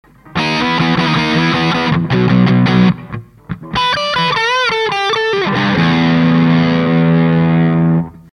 Guitar MoonStoratoShape
Amplifier VOX AD30VT UK'80S
GAIN12時VOLUME全開
RR DISTORTION OFF(110kbMP3)